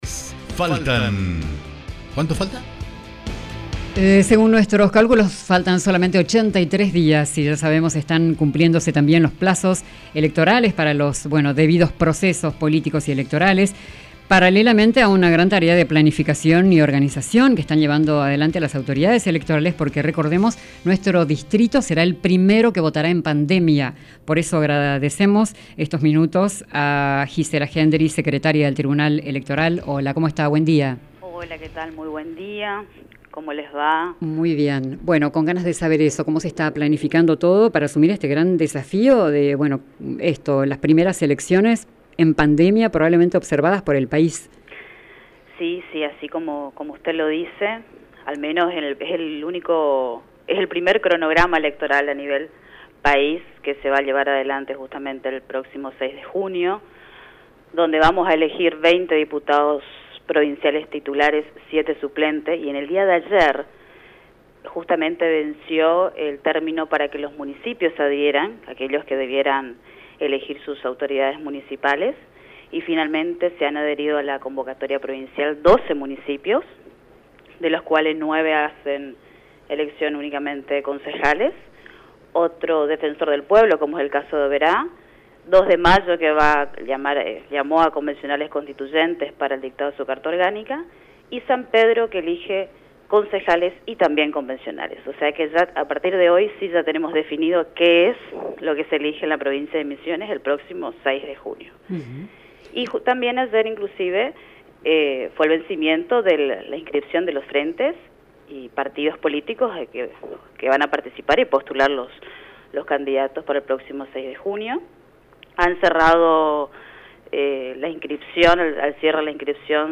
en diálogo con Radio República